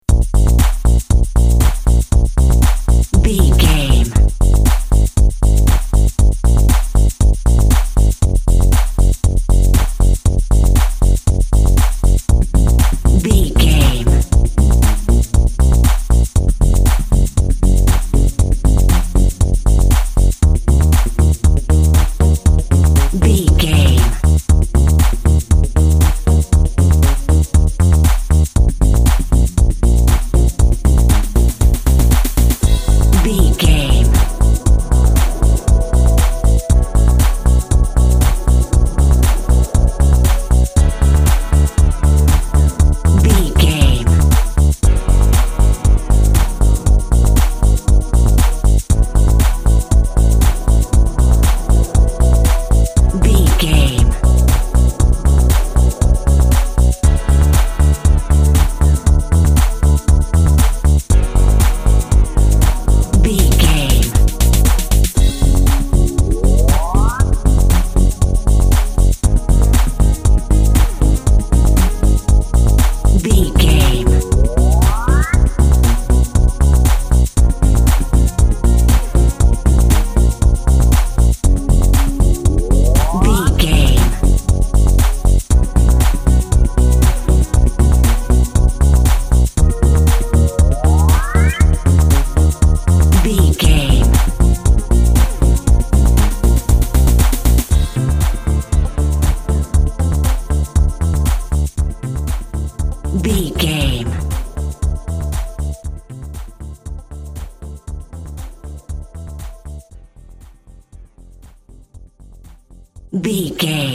Poppy Electro Music.
Aeolian/Minor
groovy
happy
fun
futuristic
uplifting
optimistic
strings
synthesiser
drums
drum machine
dance
techno
trance
synth lead
synth bass
Synth Pads